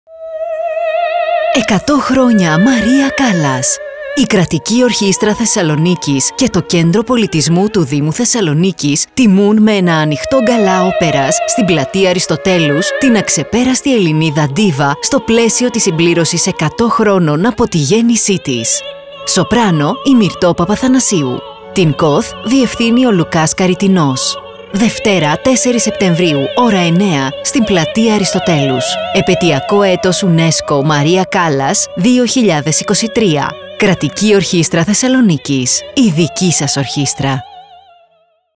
Ραδιοφωνικό σποτ